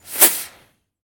rocketaim.ogg